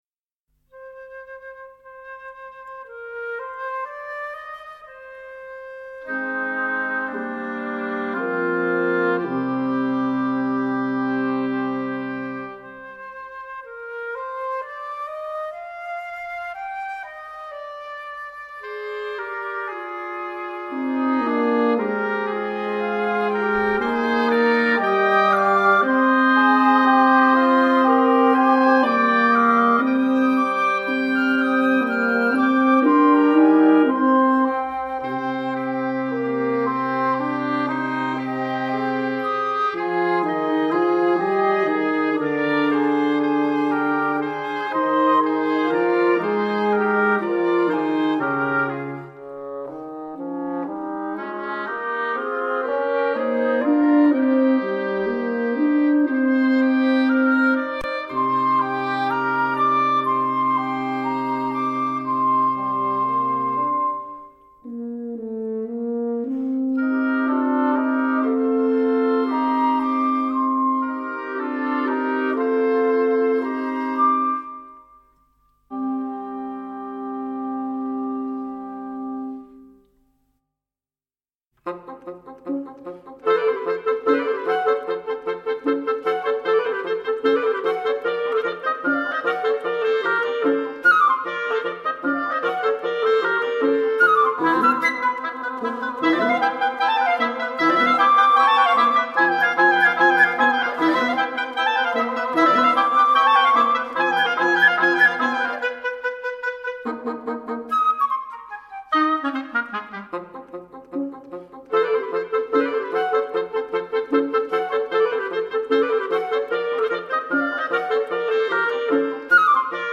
flute
oboe
clarinet
horn
bassoon